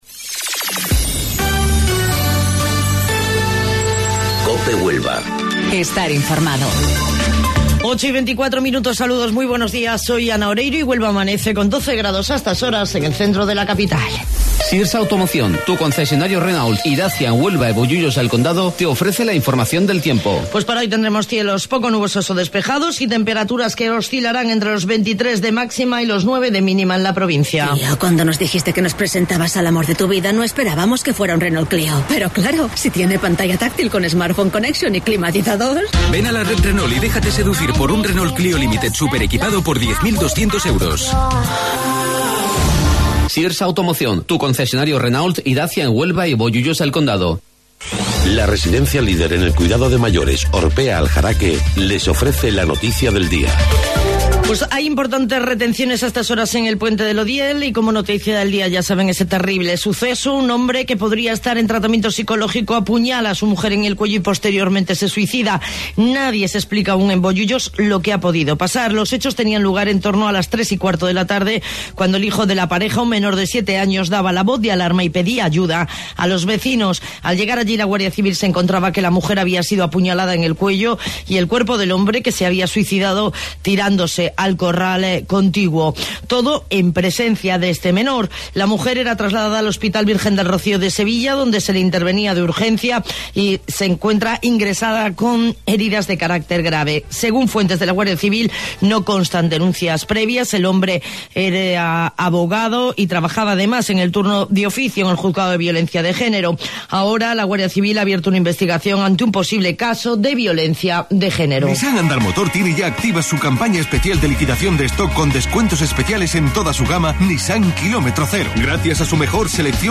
AUDIO: Informativo Local 08:25 del 14 de Marzo